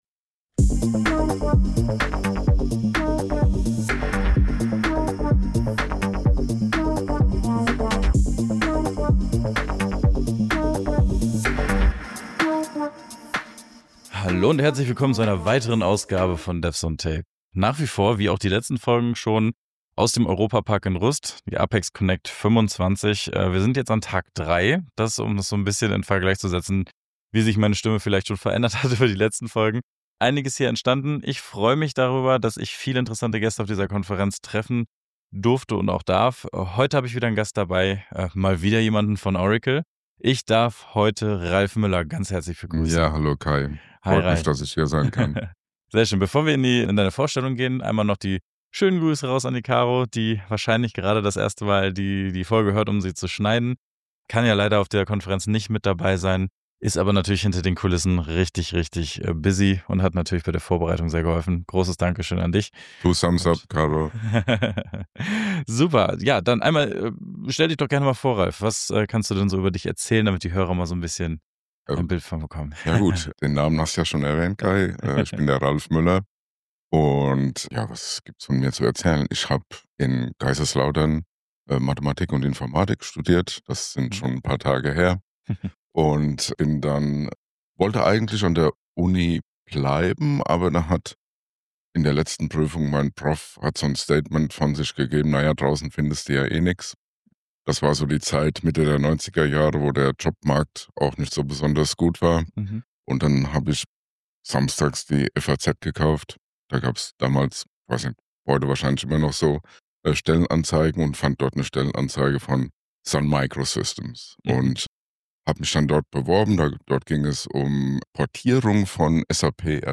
Play Rate Listened List Bookmark Get this podcast via API From The Podcast "Devs on Tape" ist ein Podcast, der sich mit allen Themen rund um die professionelle Software-Entwicklung beschäftigt. Zwei kreative Köpfe teilen Ihr Wissen und ihre Erfahrungen im Bereich Development, DevOps, Vereinsarbeit und vielem mehr.